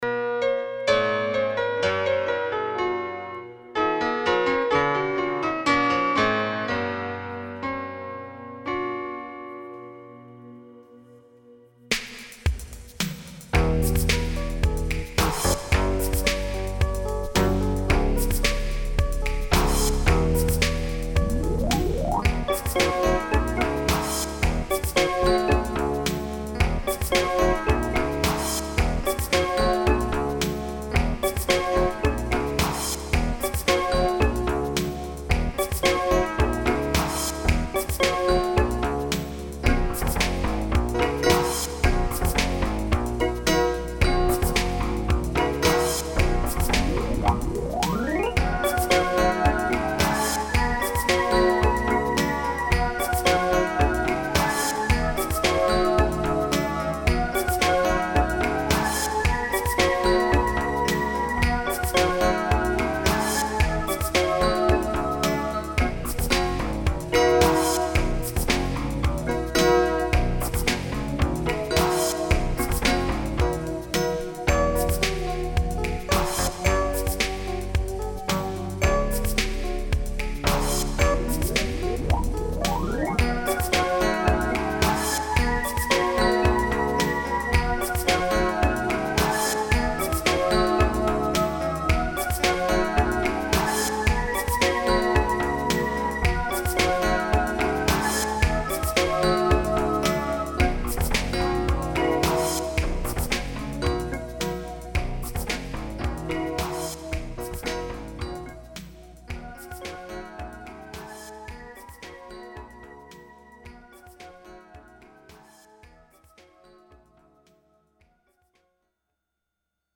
INSTRUMENTAL ALBUMS